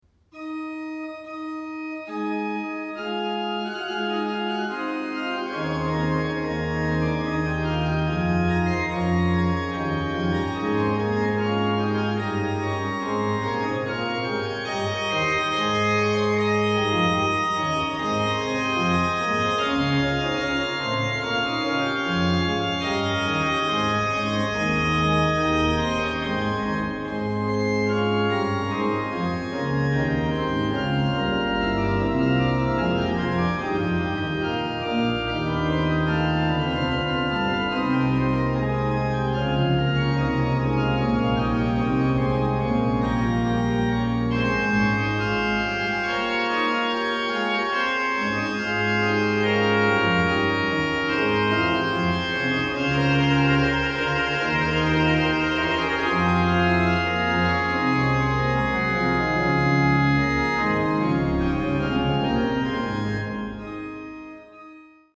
What better instrument to play it on other than the splendid new Bach organ in Thomaskiche Leipzig.
Registrations are clear, incisive and perfectly balanced, full of colour and show off the wonderful voicing of the instrument.